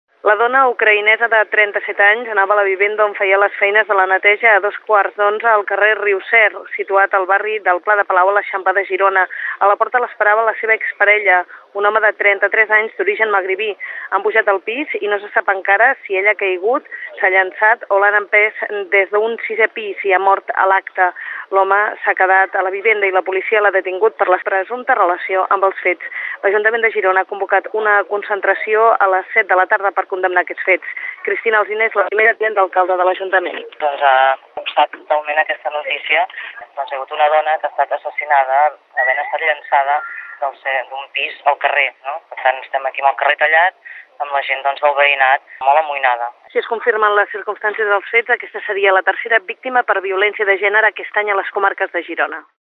Informatius: crònica de successos - Catalunya Ràdio, 2010